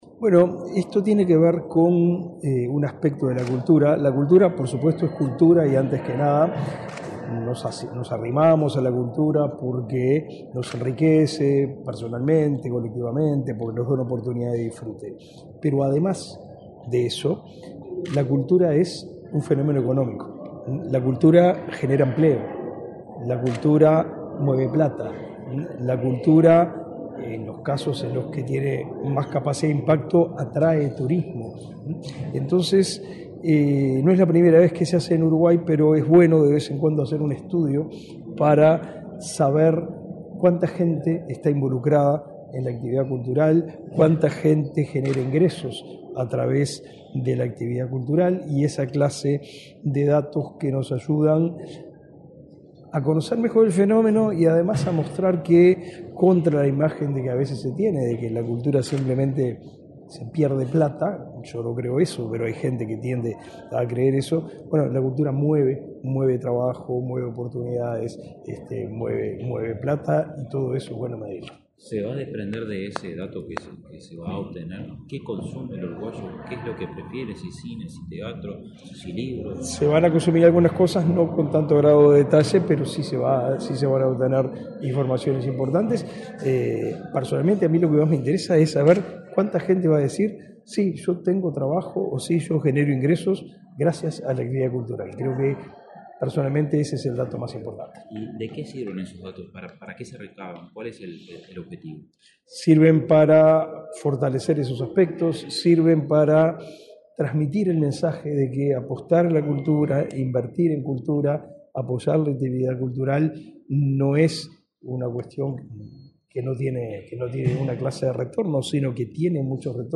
Declaraciones del ministro de Educación y Cultura, Pablo da Silveira
Declaraciones del ministro de Educación y Cultura, Pablo da Silveira 12/08/2024 Compartir Facebook X Copiar enlace WhatsApp LinkedIn Este lunes 12 en la Torre Ejecutiva, el ministro de Educación y Cultura, Pablo da Silveira, dialogó con la prensa, antes de participar en el acto de firma de un acuerdo con autoridades del Instituto Nacional de Estadística para realizar una encuesta a fin de concretar la Cuenta Satélite de Cultura del Uruguay.